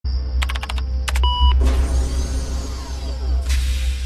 Clavier + bip (64 Ko)
clavier-bip.mp3